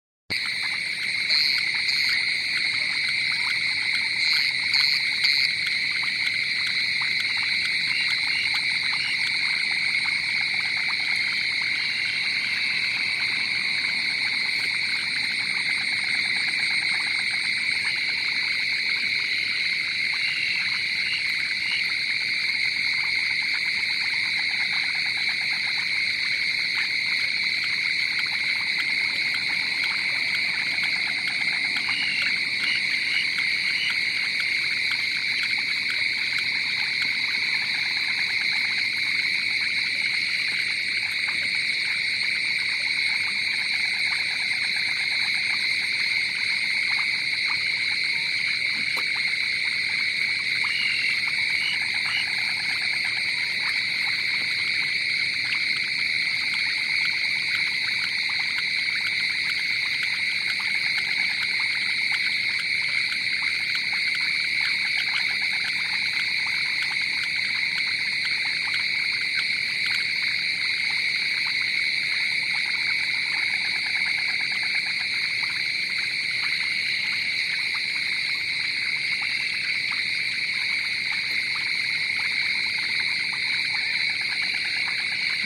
Midnight froglings, Goa
This was recorded near a field after the rain around midnight where frogs were having a huge discussion about meeting their future partners.